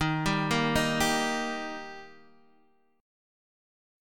D# chord